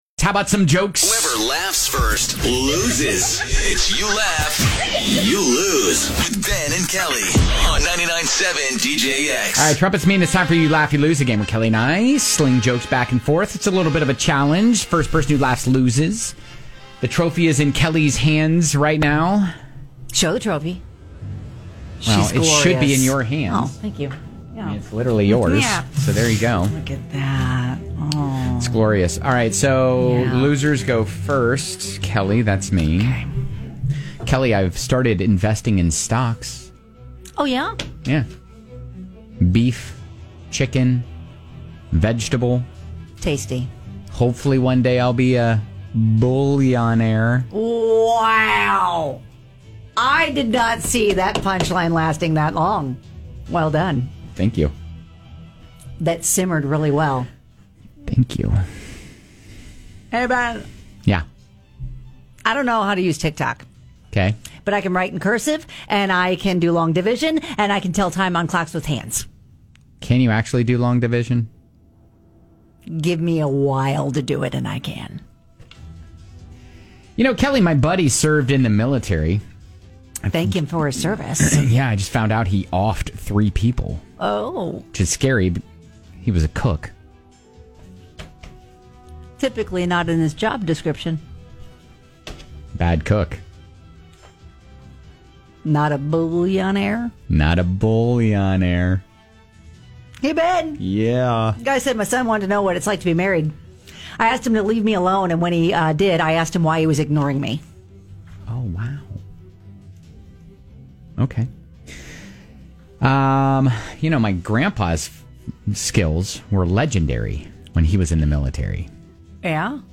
toss jokes back and forth until someone laughs!!